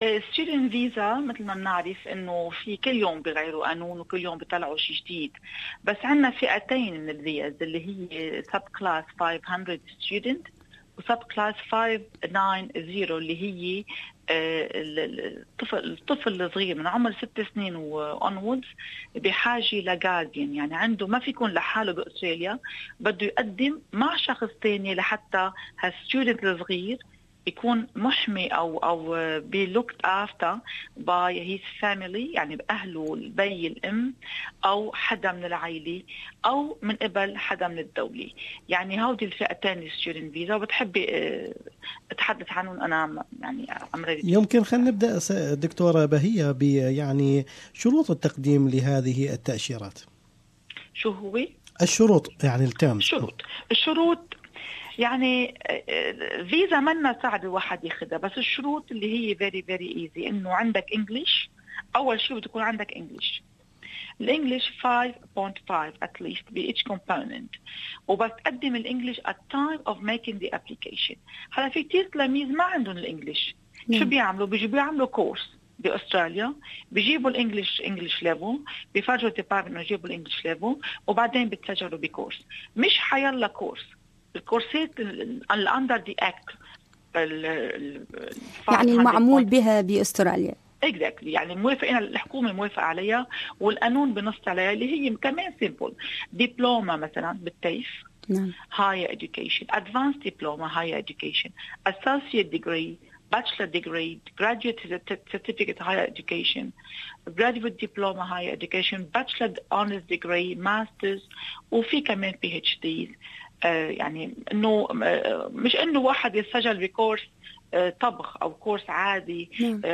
المقابلة التالية